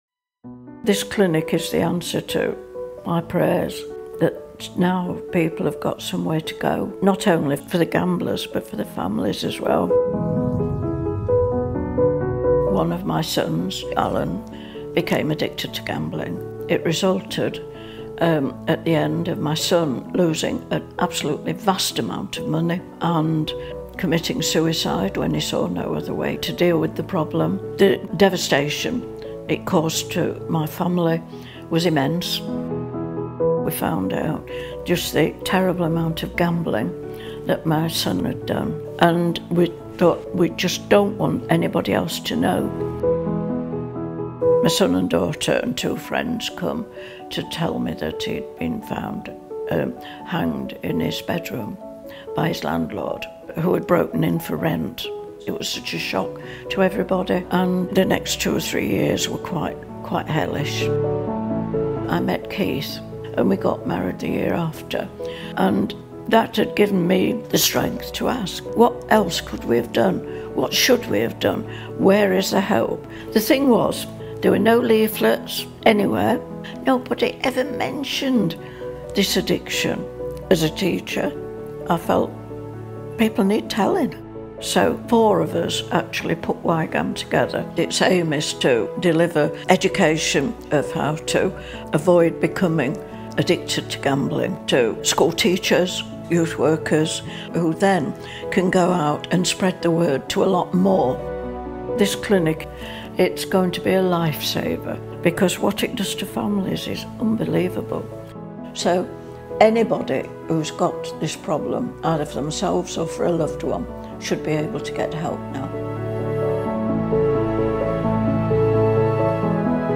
a gambling-related suicide story – GA Speaker Audios